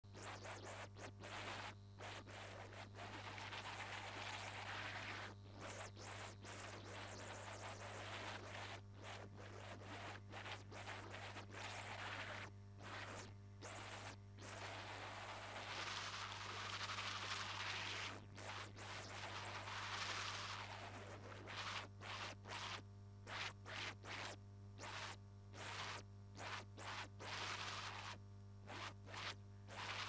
CD音源 も良く流れる、
Noise.mp3